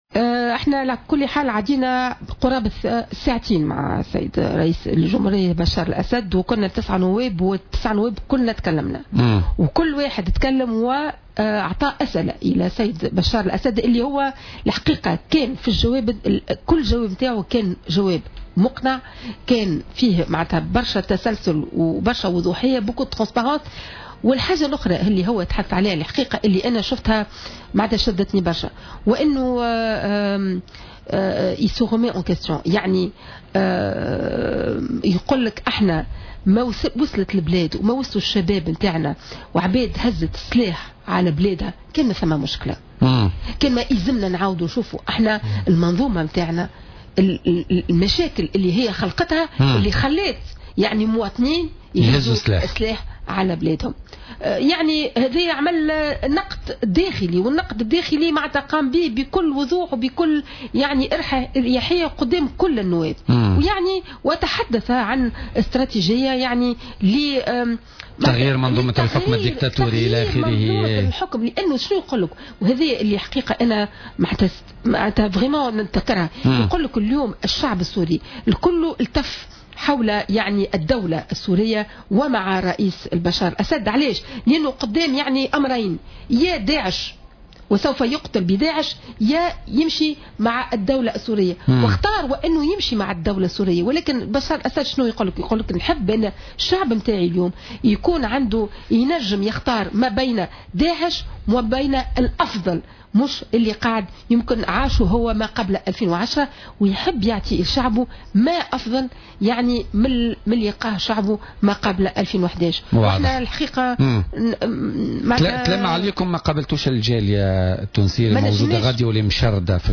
وأكدت الشتاوي، في حوار مع الجوهرة أف أم، اليوم الأربعاء خلال برنامج بوليتيكا، أن قنصل تونس في سوريا تعمد إغلاق هاتفه الجوال، أثناء تواجد الوفد البرلماني في سوريا، بداية شهر أوت الحالي، ليتم إلغاء اللقاء المقرر في القنصلية بحضور الجالية التونسية.